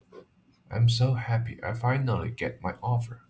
I_am_happy_get_my_offer.wav